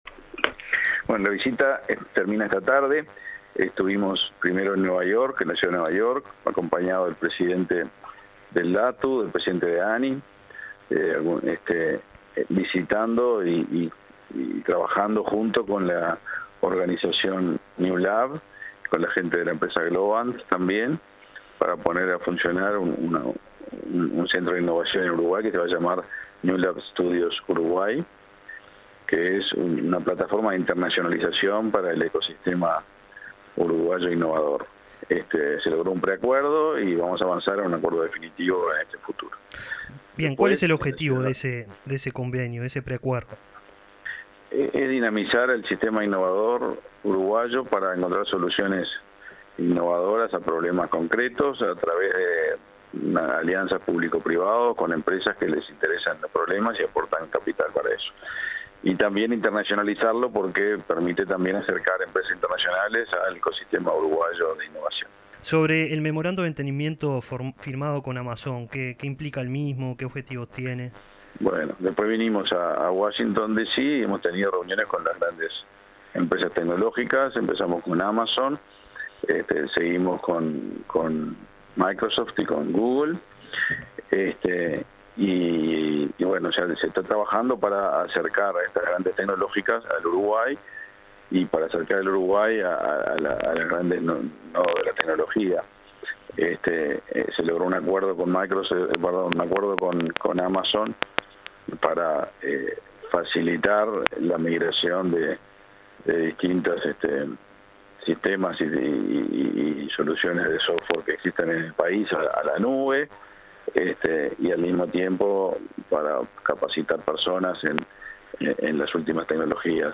Entrevista al ministro de Industria, Omar Paganini | Presidencia Uruguay